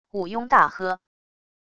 武庸大喝wav音频